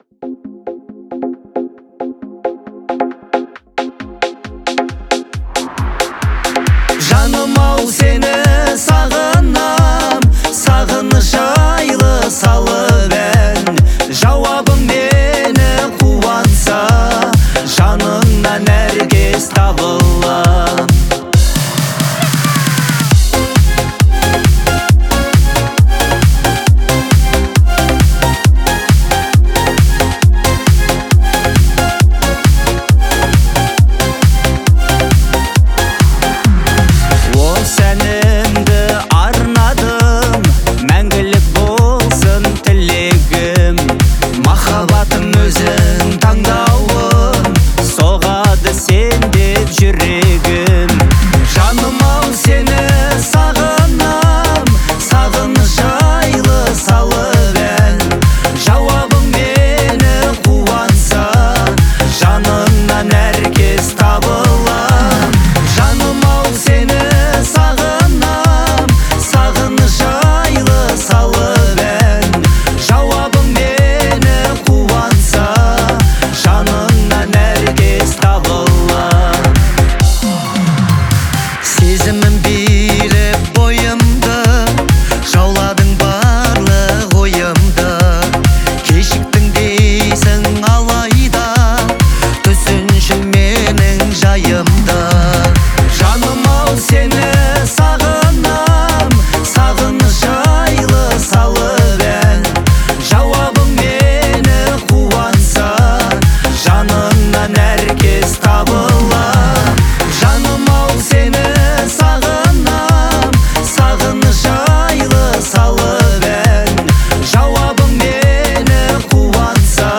• Категория: Казахские песни /